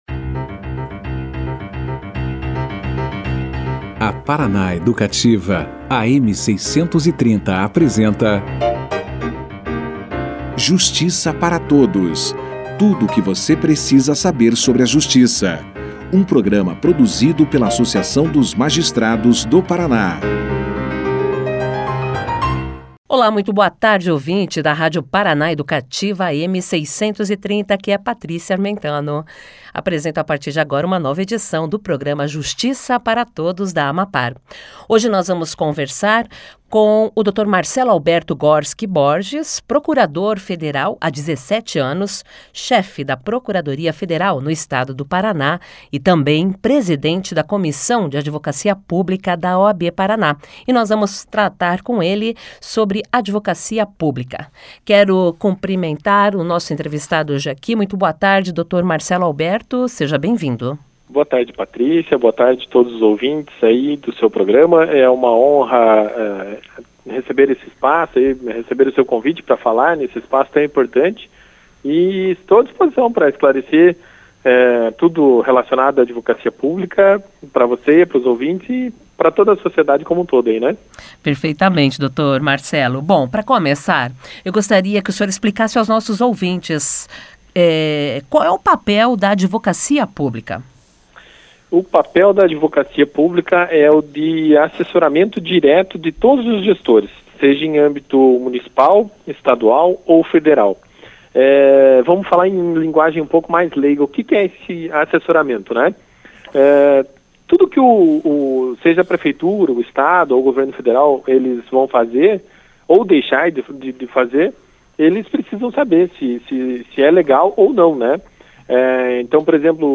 Das funções essenciais à justiça, A Advocacia Pública tem entre as suas ações institucionais relacionadas à defesa e promoção dos interesses públicos da União, dos Estados, do Distrito Federal e dos Municípios. Confira aqui a entrevista na íntegra.